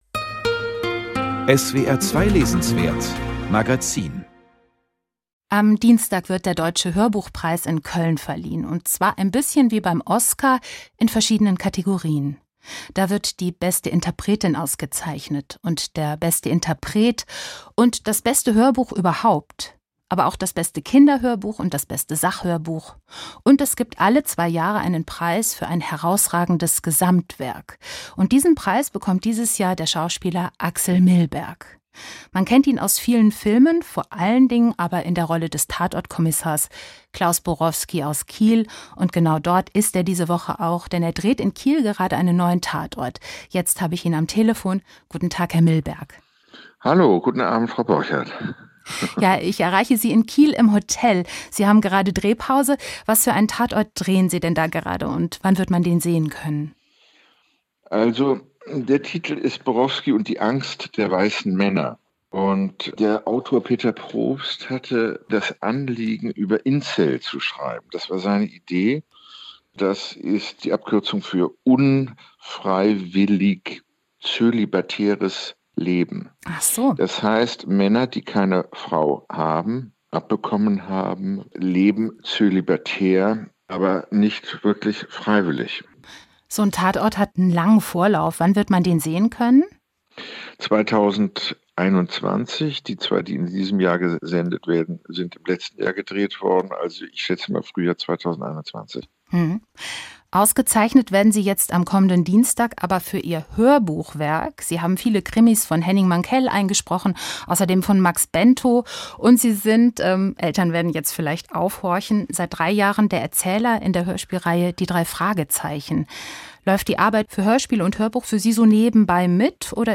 Axel Milberg erhält den Deutschen Hörbuchpreis 2020.für sein Gesamtwerk. Im Gespräch